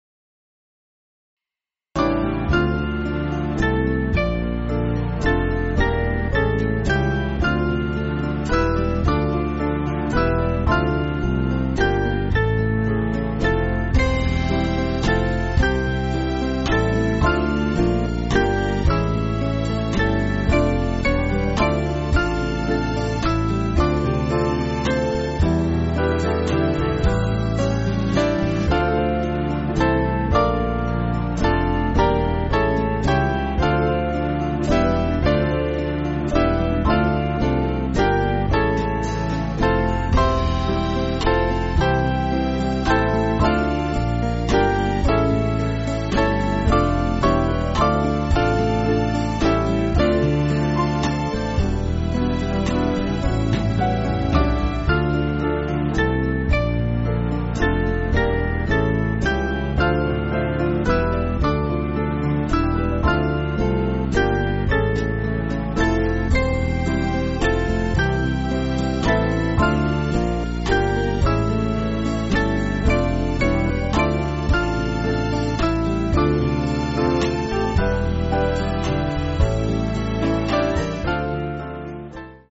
Small Band
(CM)   4/Dm